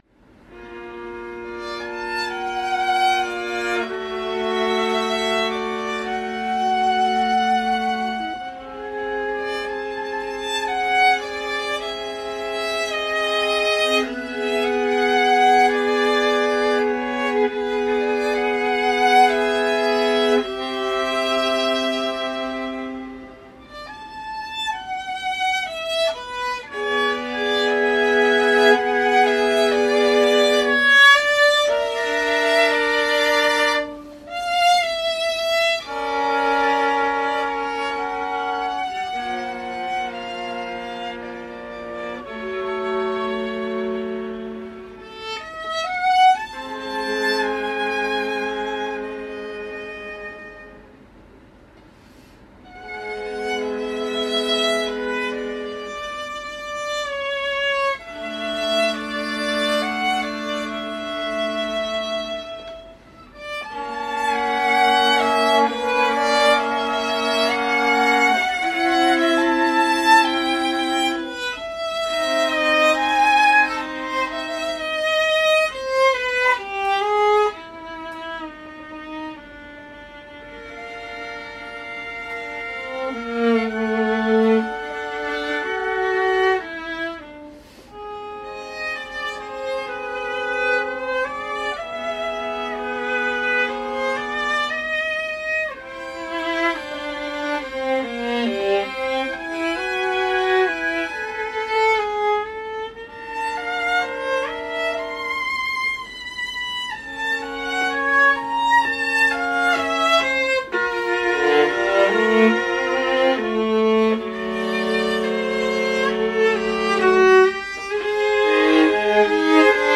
for viola duo
Rehearsal recordings of the three duets can be heard at the following links:
The chords are all triads, but they aren’t used in functional ways.
Viola_Duets1.mp3